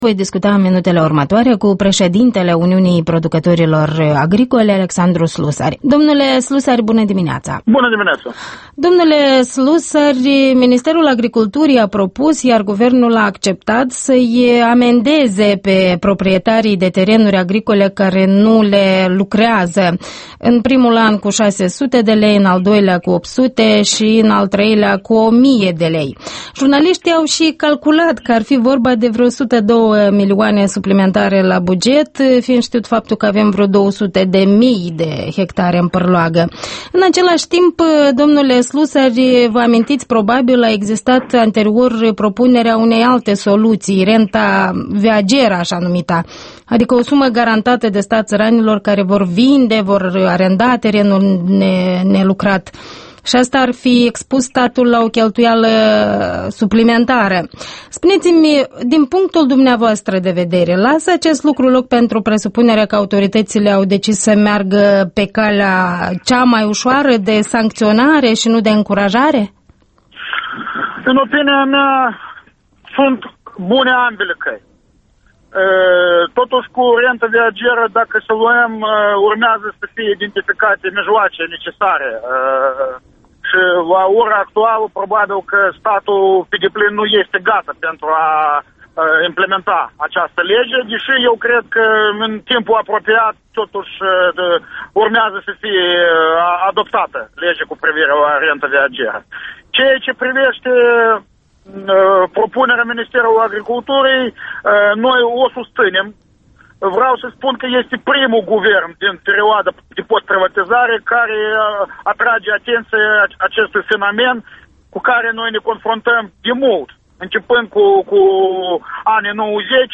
Interviul dimineții la EL: cu preşedintele Uniunii Producătorilor Agricoli, Alexandru Slusari